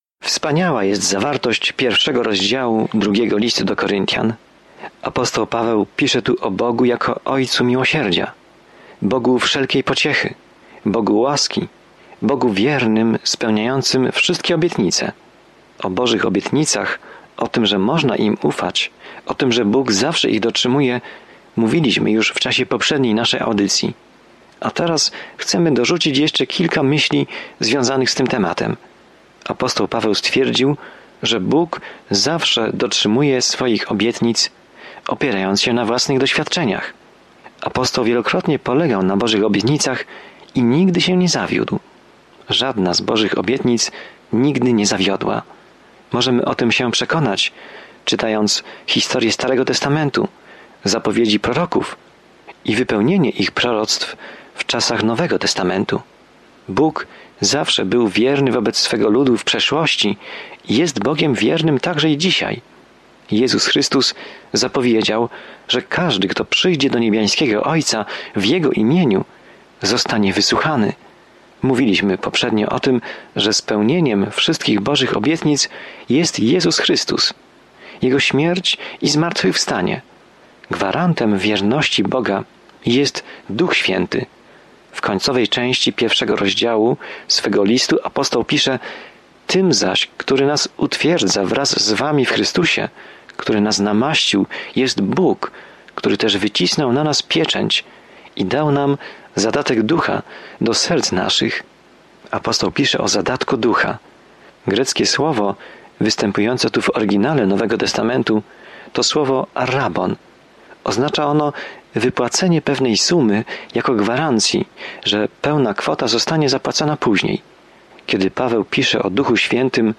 Codziennie podróżuj przez 2 List do Koryntian, słuchając studium audio i czytając wybrane wersety ze słowa Bożego.